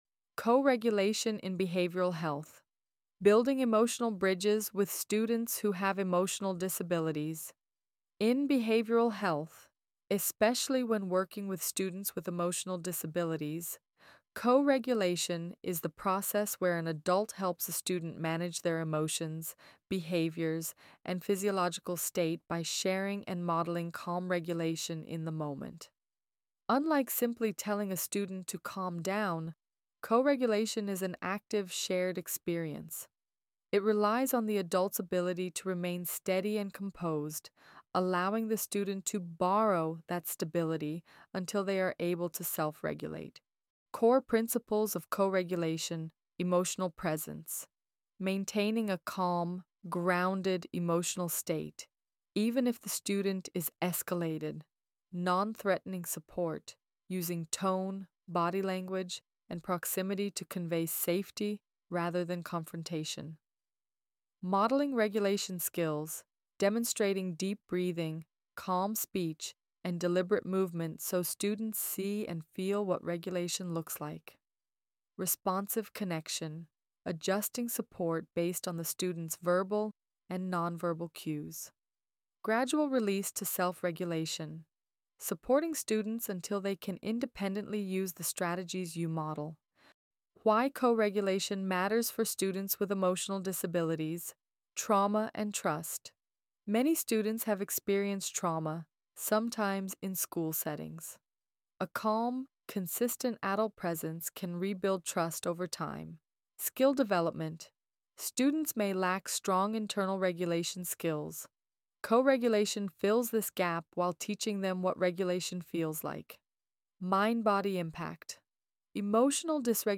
AI Narration